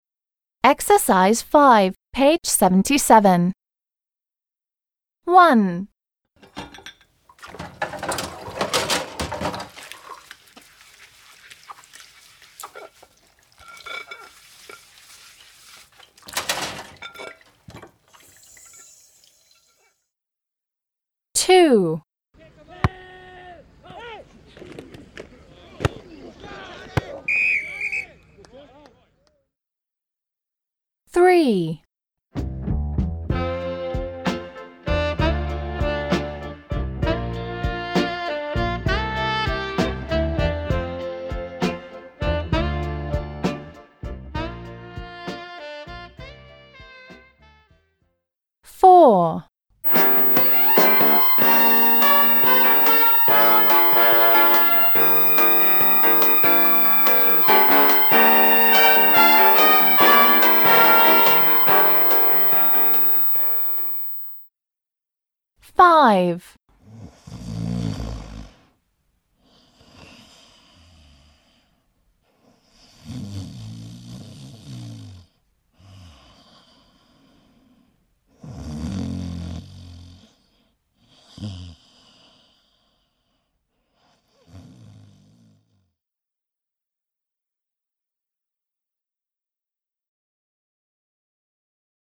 5. Listen to the sounds. Match the people to what they have been doing. Make up sentences.
1-E. John wash dishes − Джон моет посуду
2-A. the kids play football − дети играют в футбол